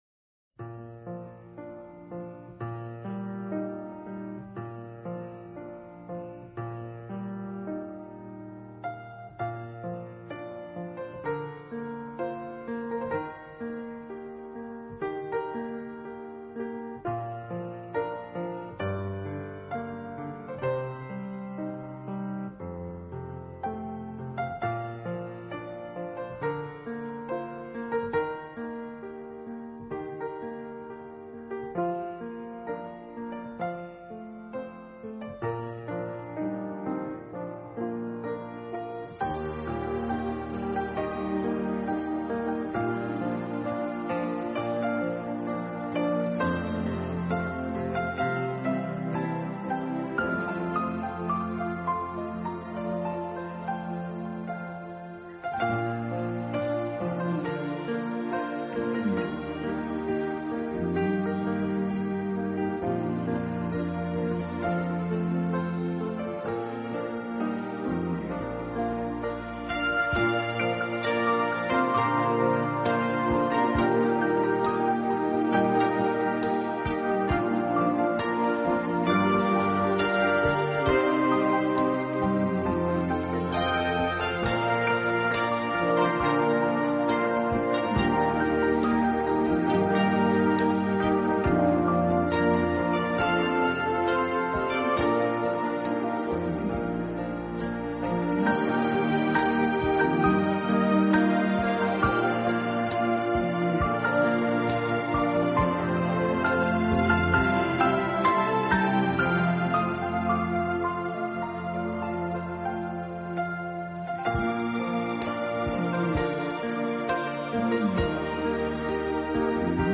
音乐类型：New Age
他以钢琴为主，管弦乐为辅的方式将这十几首乐曲重新诠释，让曲子有更悠然而